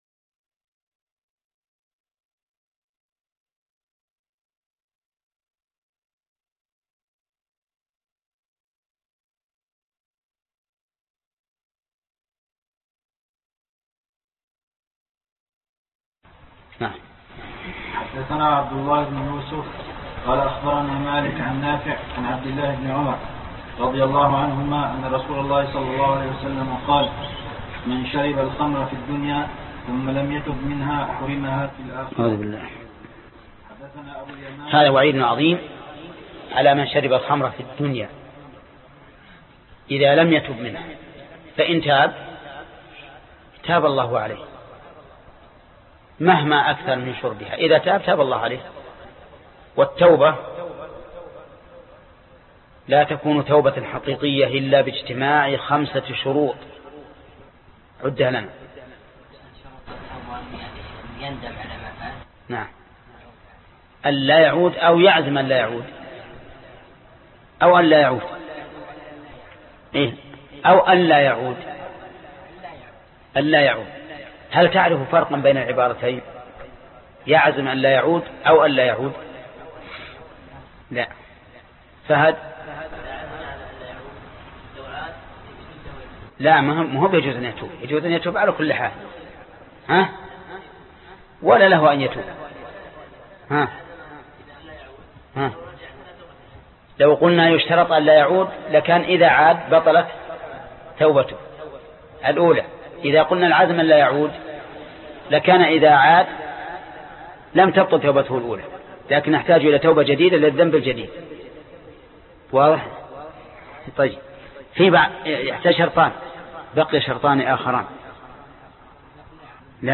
الدرس 2 باب قوله تعالى إنما الخمر والميسر والأنصاب..2 (شرح كتاب الأشربة صحيح البخارى) - فضيلة الشيخ محمد بن صالح العثيمين رحمه الله